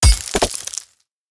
axe-mining-ore-7.ogg